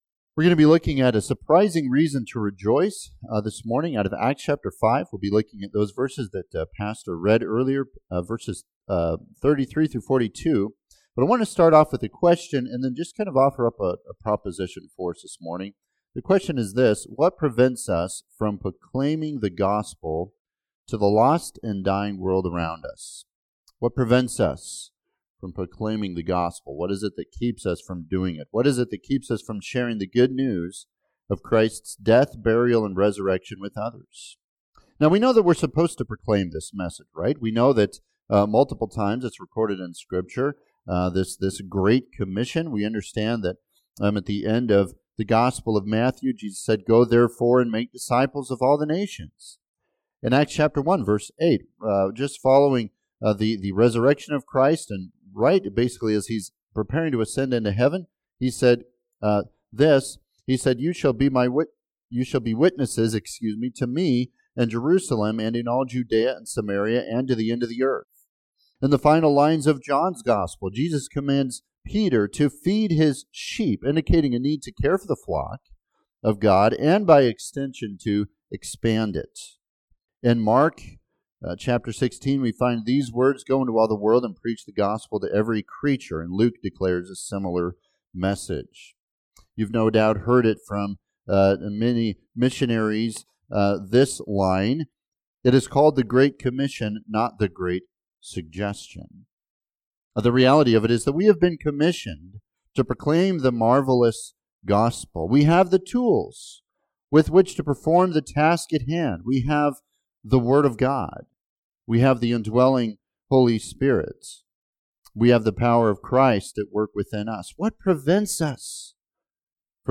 Passage: Acts 5;33-42 Service Type: Morning Sevice